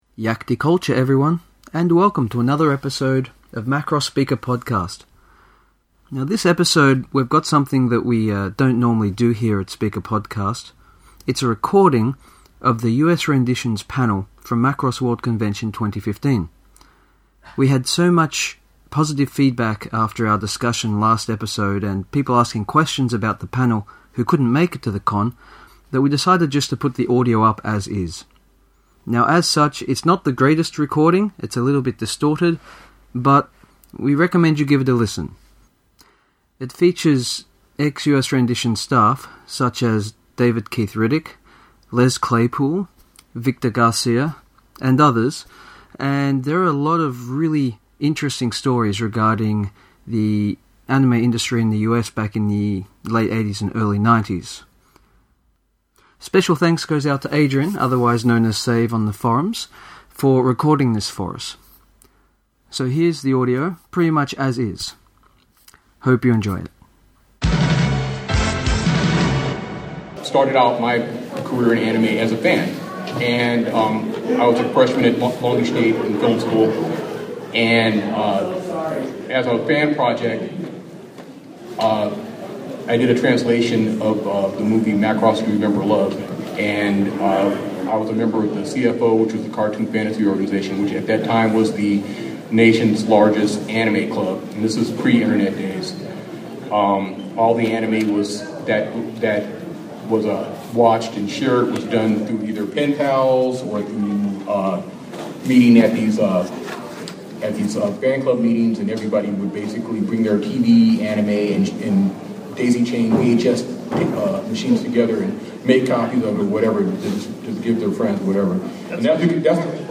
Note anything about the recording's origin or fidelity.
This panel contained some great tales about DYRL, Orguss, Macross 2, meeting Shoji Kawamori and much much more. So, while we don’t usually do this here at SpeakerPODcast, this episode consists primarily of the audio from the U.S Renditions panel for those who were unable to make it to the Con.